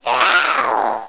swoop2.wav